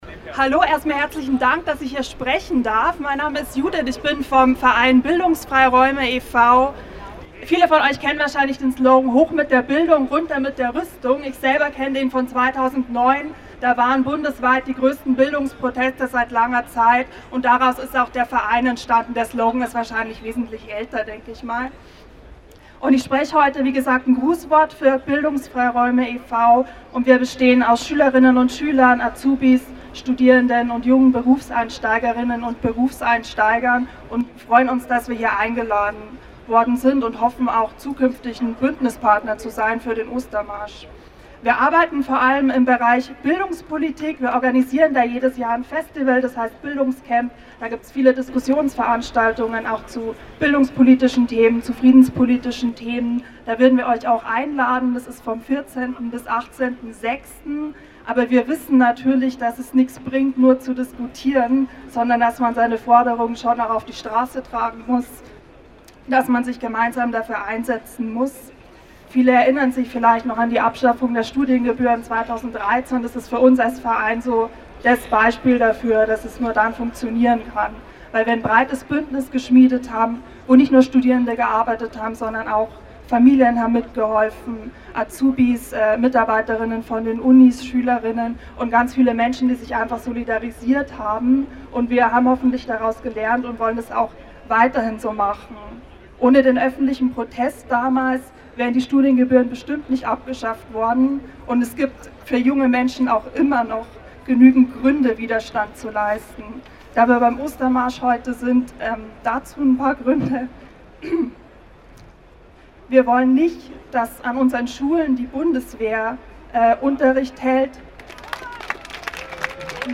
am Stachus
OM2017_4_Rede_Bildungsfreiraeume_Stachus.mp3